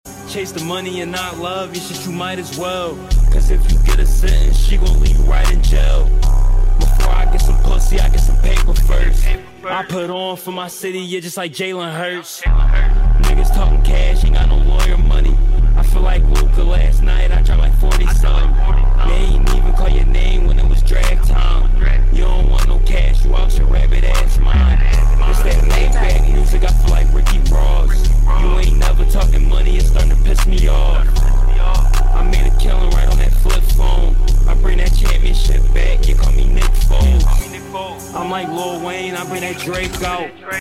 V8 SOUNDTRACK AT END 😛 sound effects free download
filmed in mexico driven by a professional